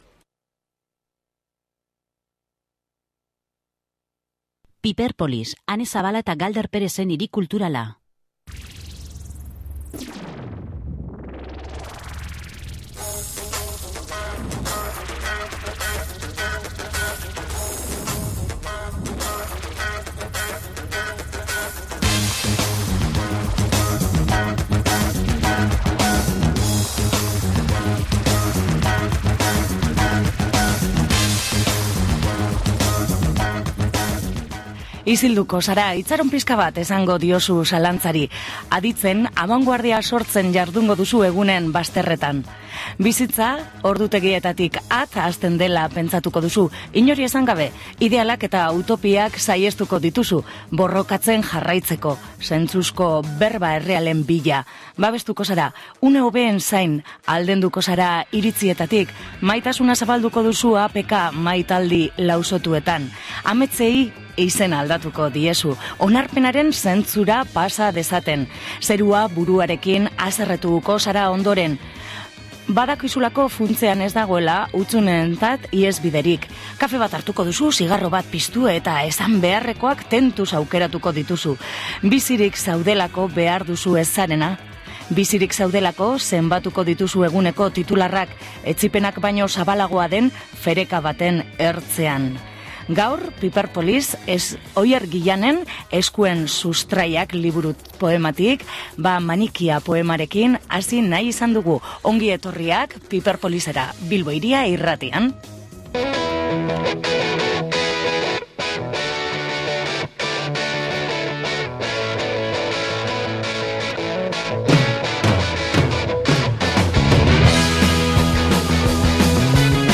Alboka motak eta baliabideak ikertu ditu bertan. Ikerketaz gain diskoan hezortzi kantu grabatu dituzte, jatorri, estilo eta garai askotarikoak. Batzuk euskal tradizioko piezak, beste batzuk munduko folkloretik hartutakoak.